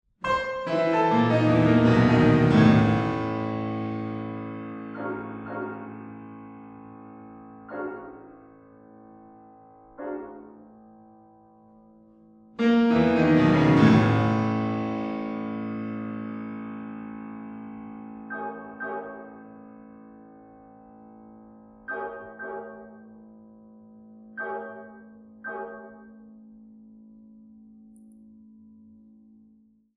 for two pianos and percussion
Description:Classical; chamber music
Instrumentation:Piano (2), percussion
Vestry Hall, Ealing, London